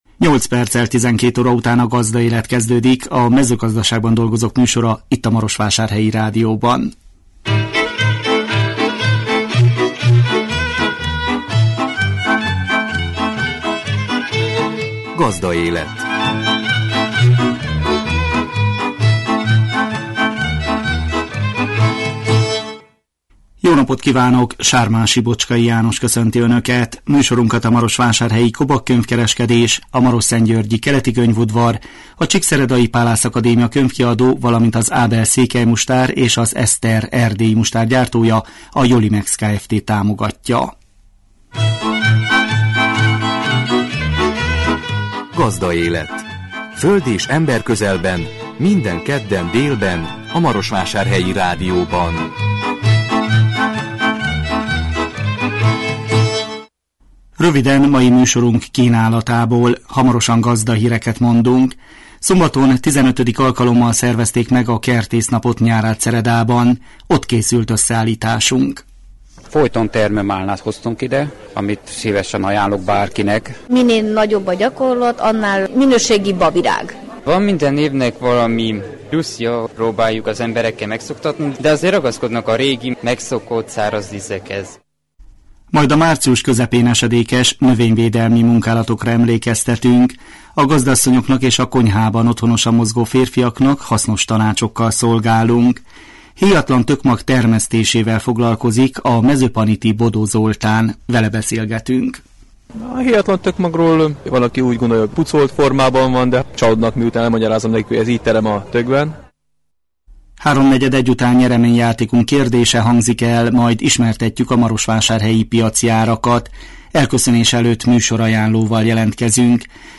A 2017 március 7-én jelentkező műsor tartalma: Gazdahírek, Szombaton XV. alkalommal szervezték meg a Kertésznapot Nyárádszeredában. Ott készült összeállításunk. Majd a március közepén esedékes növényvédelmi munkálatokra emlékeztetünk.
Vele beszélgetünk. Háromnegyed egy után nyereményjátékunk kérdése hangzik el, majd ismertetjük a marosvásárhelyi piaci árakat.